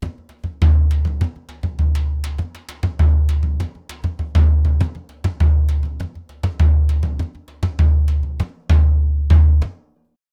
Surdo 1_Samba 100_1.wav